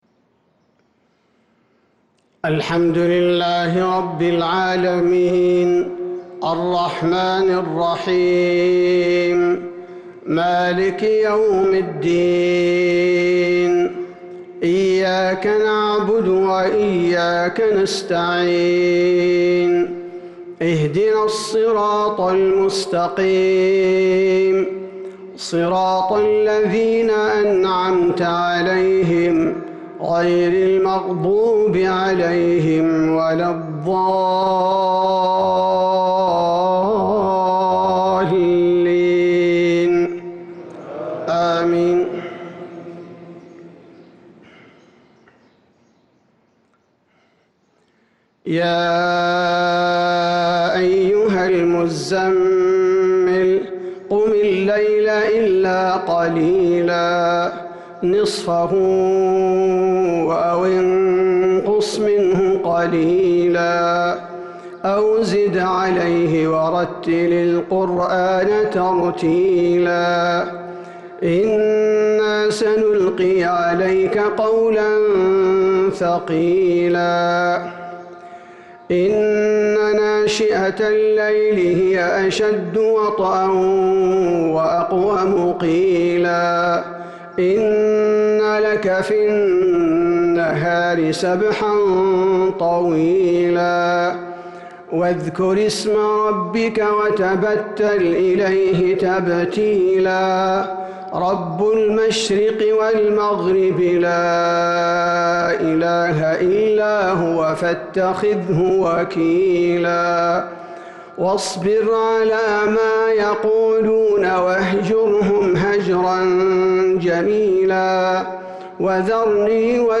فجر ١-٩-١٤٤٣هـ سورة المزمل Fajr prayer from surah al-Muzammil 2-4-2022 > 1443 🕌 > الفروض - تلاوات الحرمين